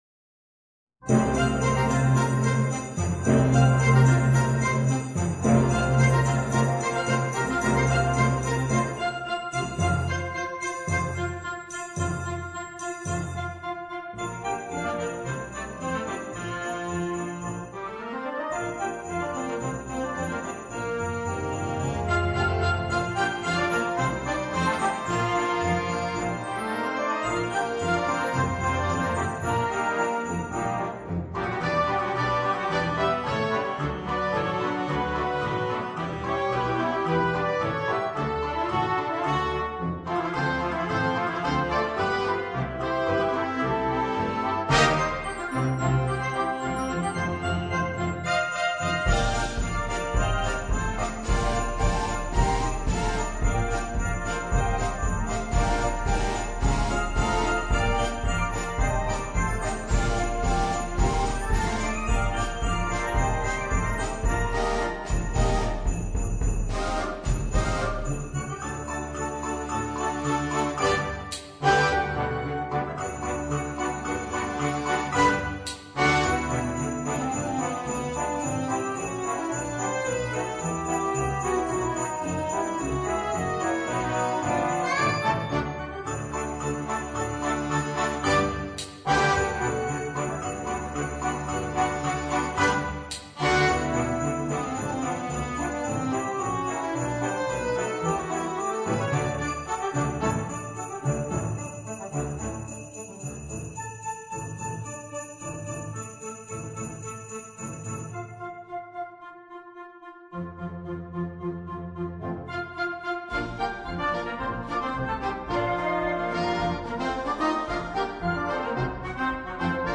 BRANI DI NATALE , MUSICA PER BANDA